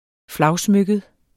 Udtale [ ˈflɑwˌsmøgəd ]